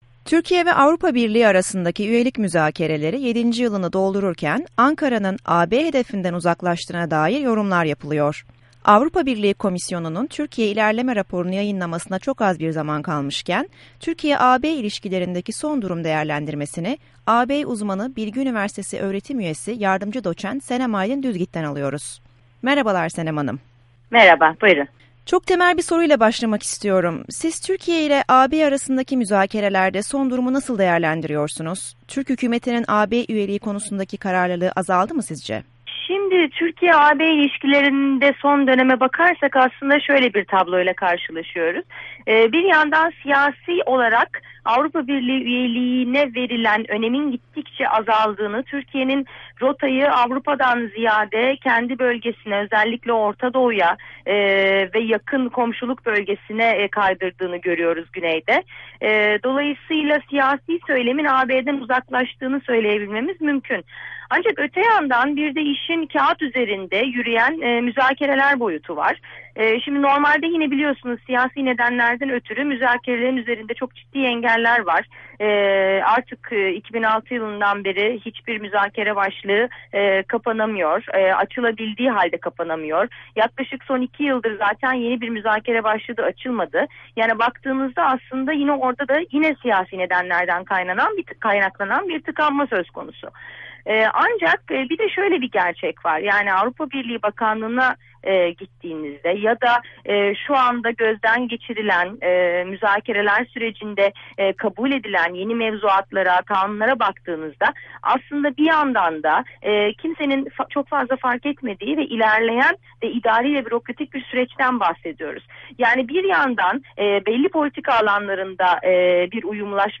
AB söyleşisi